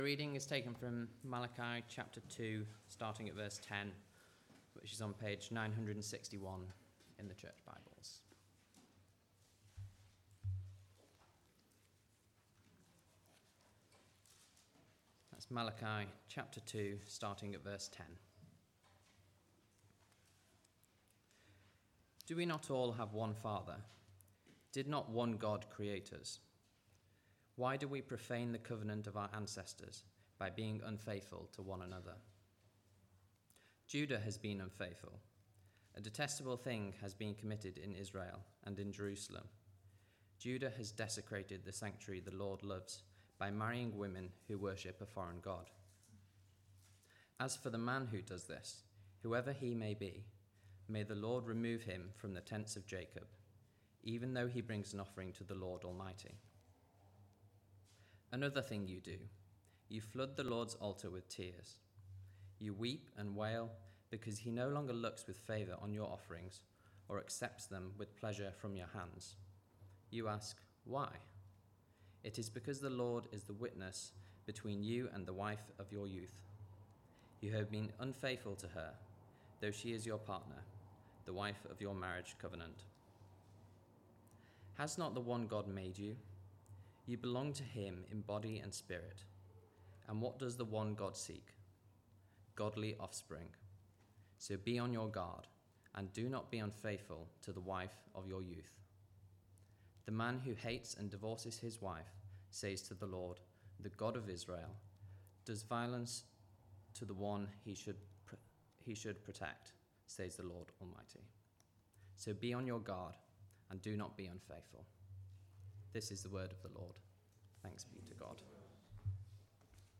Not taking God at his word Passage: Malachi 2:10-16 Service Type: Weekly Service at 4pm « Leaders who despise God’s name Where is the God of Justice?